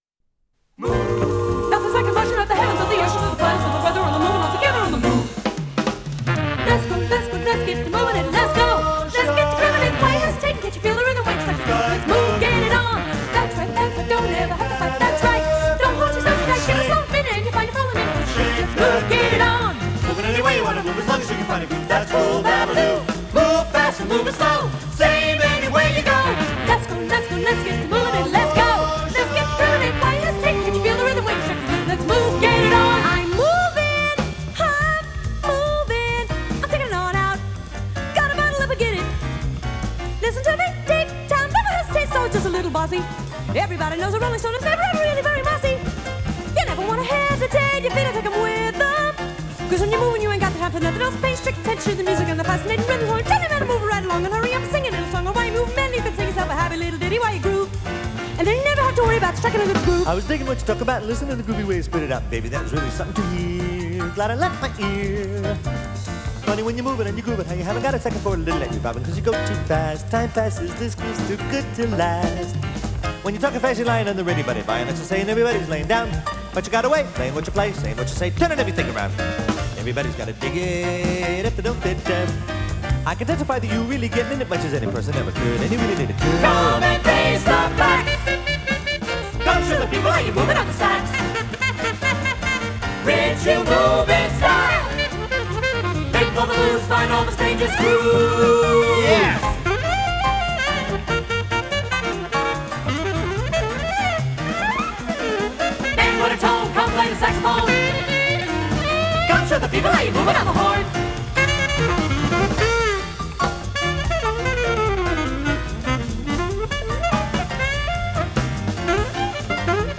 с профессиональной магнитной ленты
Скорость ленты38 см/с
МагнитофонМЭЗ-109М